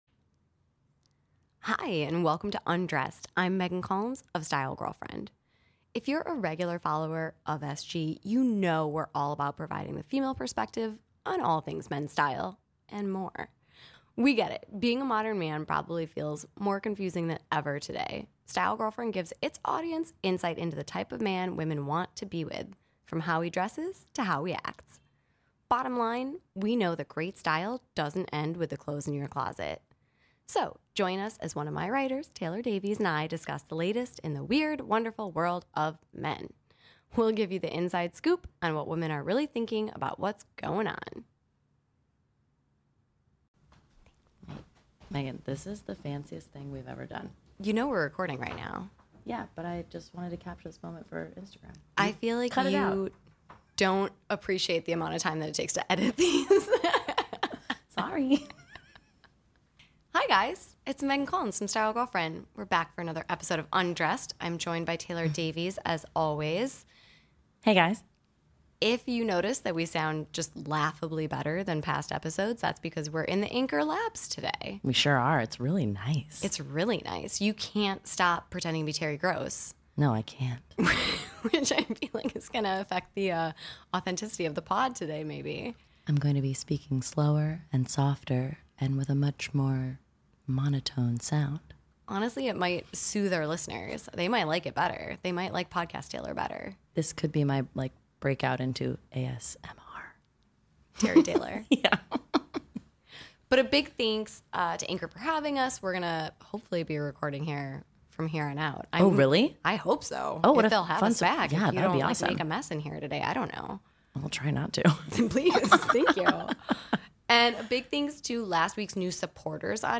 Recording in Anchor's bright and shiny new Podcast Labs, you'll also notice "laughably better" audio quality, so please..enjoy that!